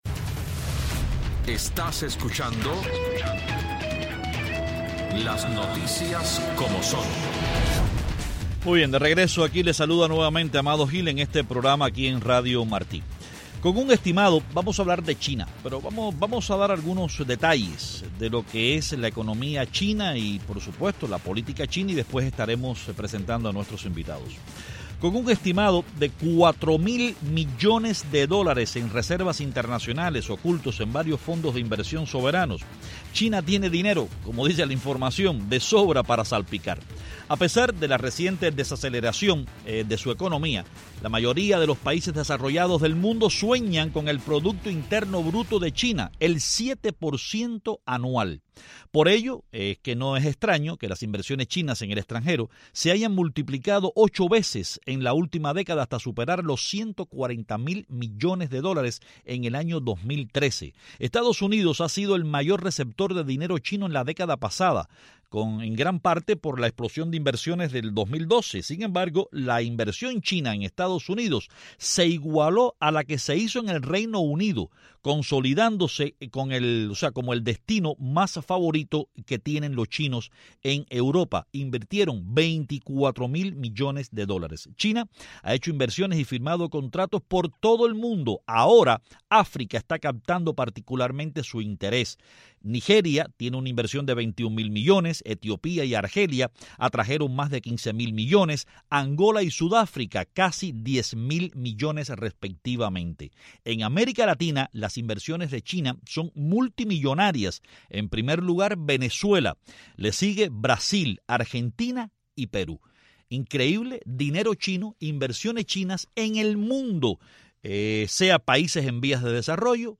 En los últimos años, China ha incrementado sus inversiones en el exterior a gran velocidad. Hoy analizamos este fenómeno con tres expertos.